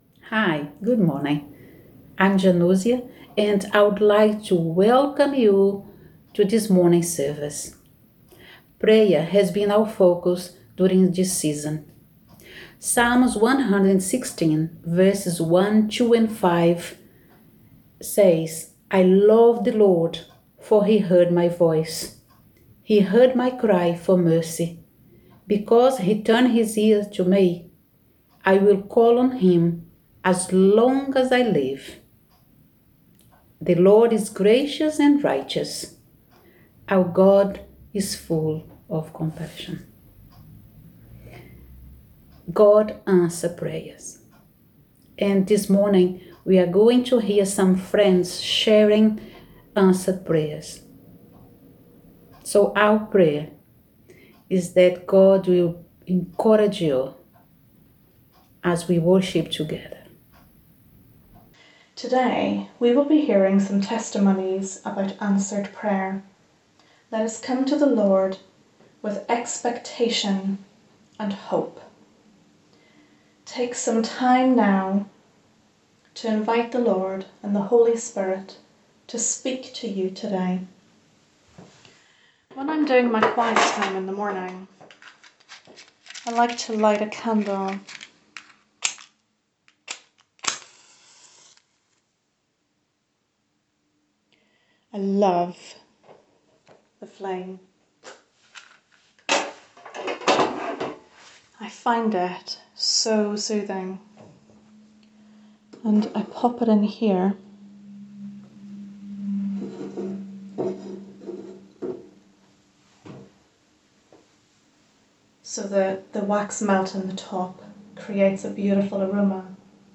Pre-recorded video and audio.
Morning Service